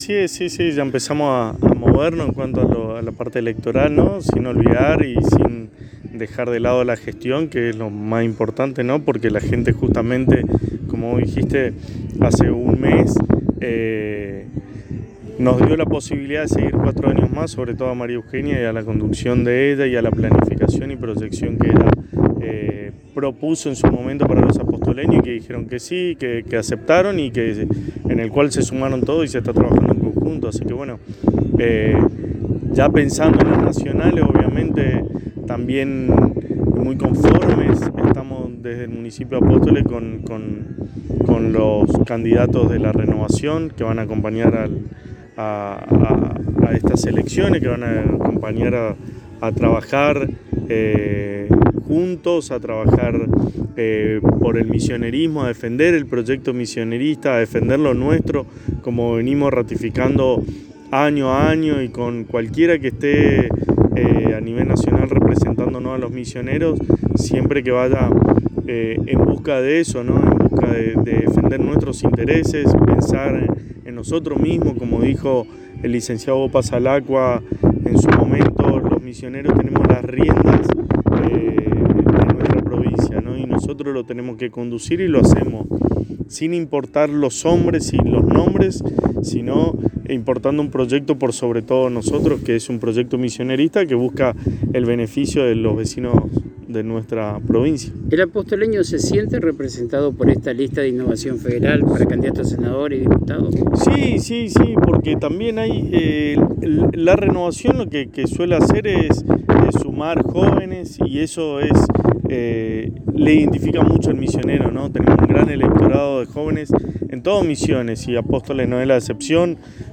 En diálogo exclusivo con la ANG, el Secretario de Gobierno de Apóstoles Gastón Casares, manifestó que ya están trabajando con miras a las PASO y a las generales de octubre sin dejar de lado la gestión, teniendo en cuenta que la ciudadanía de Apóstoles la reeligió a María Eugenia Safrán por cuatro años más.